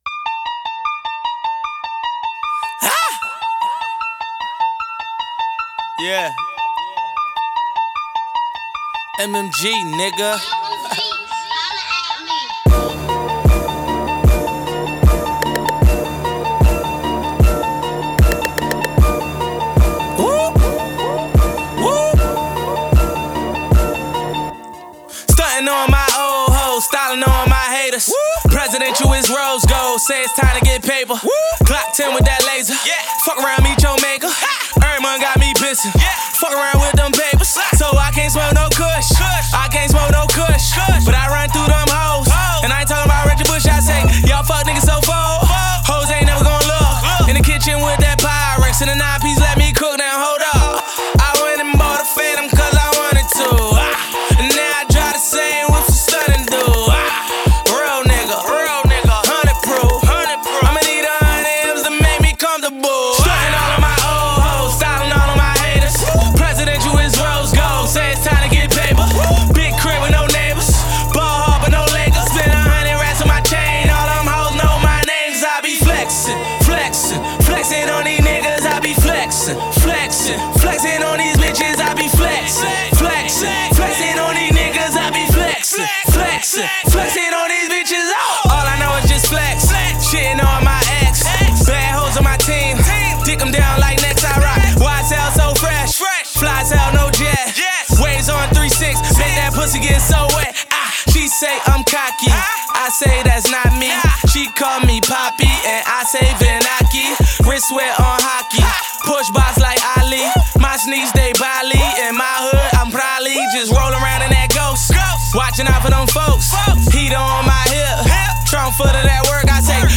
A new single
Dope rhymes and dope production